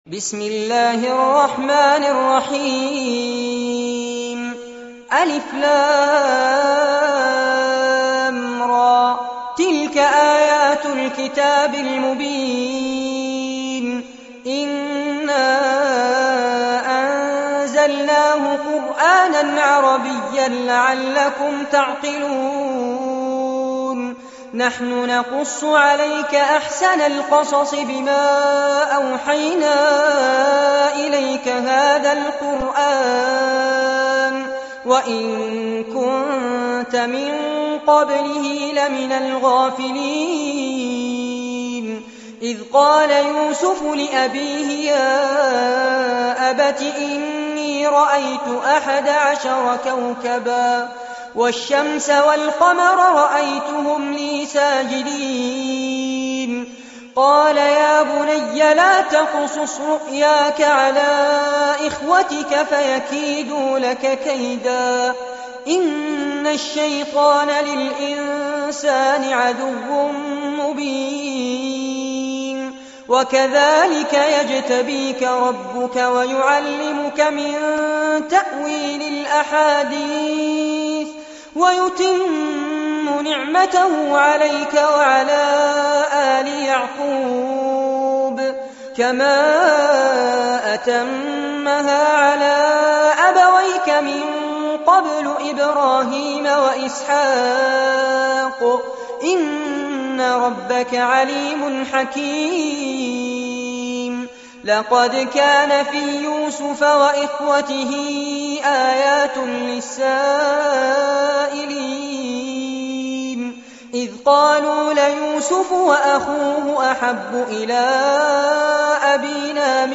سورة يوسف- المصحف المرتل كاملاً لفضيلة الشيخ فارس عباد جودة عالية - قسم أغســـــل قلــــبك 2